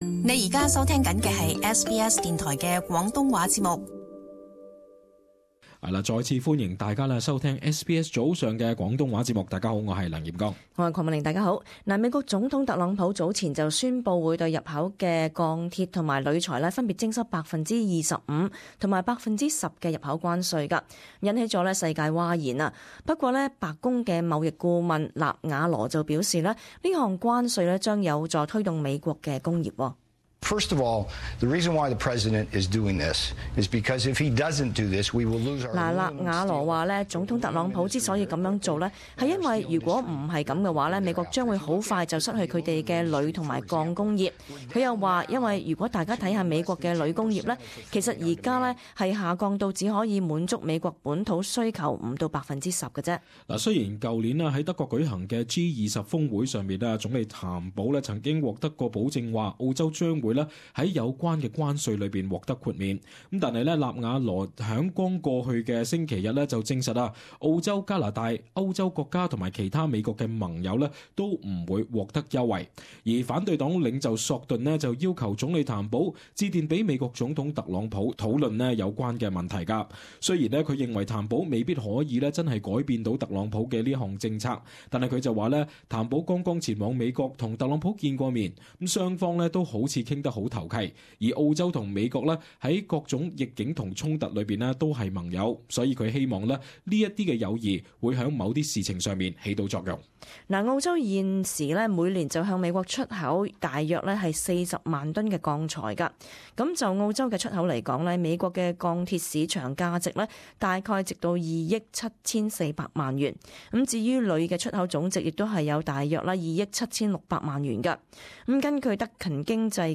【时事报导】澳冀获美豁免钢铝关税